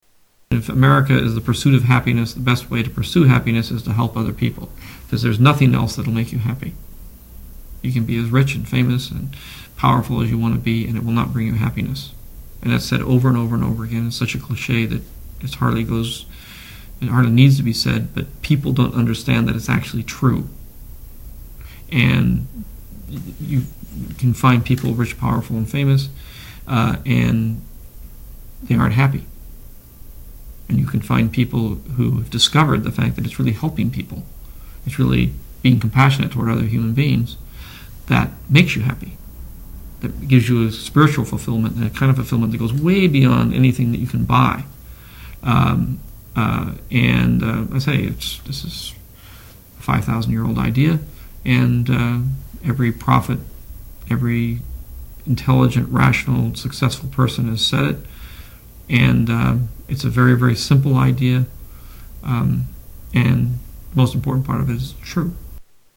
Tags: Media George Lucas audio Interviews George Lucas Star Wars Storywriter